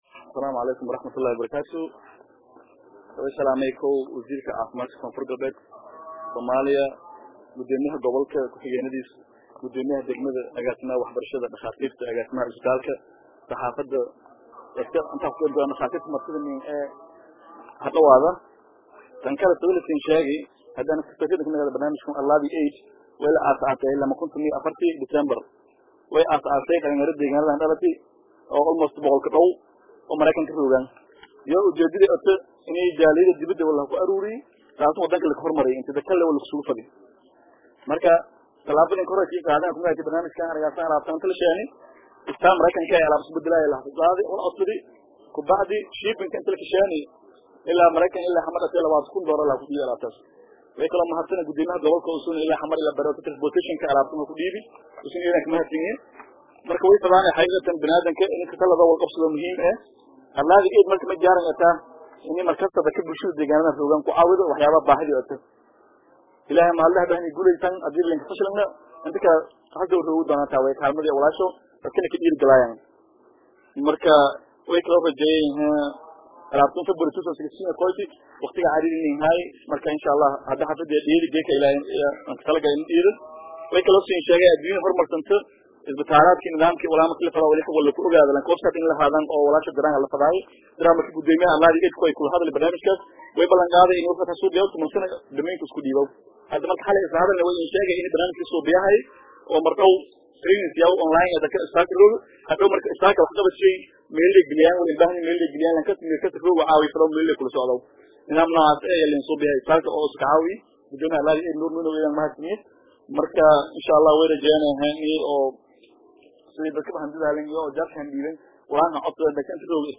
Dhamaan Mas’uuliyiintii, ka hadashey Munaasabada waxay u mahadceliyeen Ururka Gargaarka Ee Arlaadi Aid, isla markaana waxay ku amaaneen isxilqaanka ay sameeyeen.